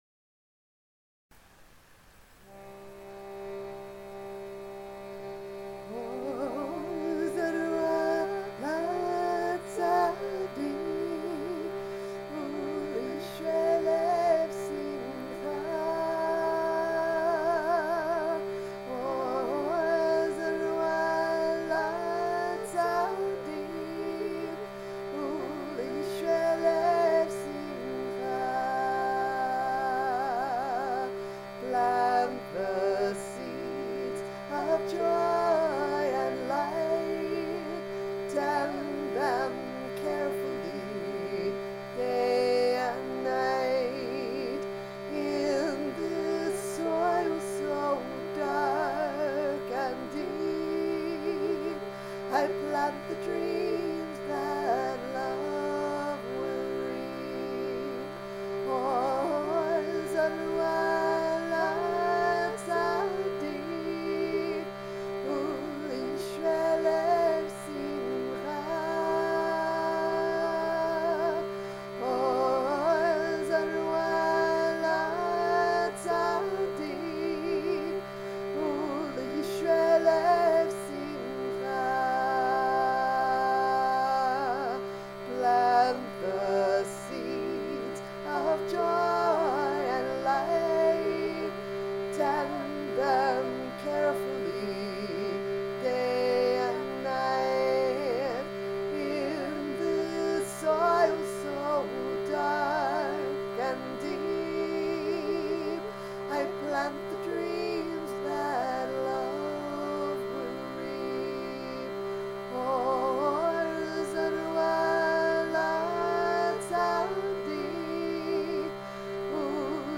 Chants, Psalms